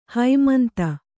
♪ haimanta